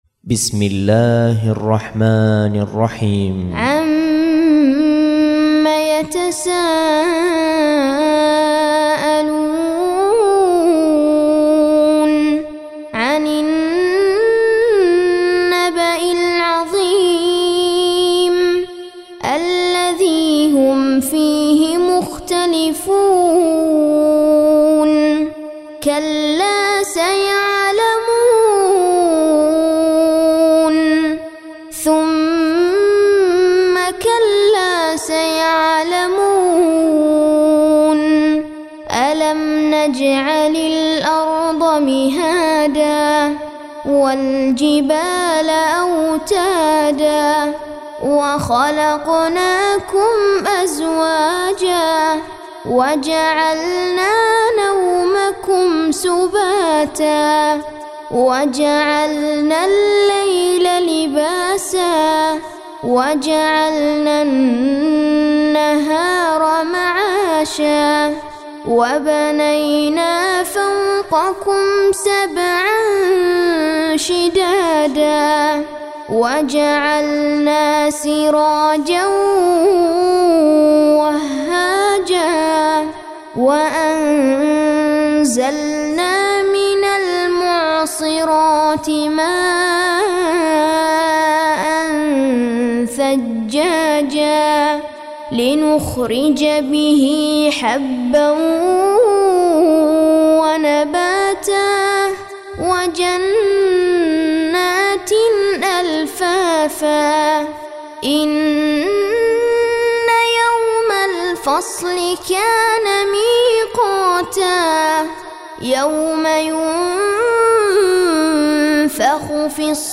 Surah Sequence تتابع السورة Download Surah حمّل السورة Reciting Muallamah Tutorial Audio for 78. Surah An-Naba' سورة النبأ N.B *Surah Includes Al-Basmalah Reciters Sequents تتابع التلاوات Reciters Repeats تكرار التلاوات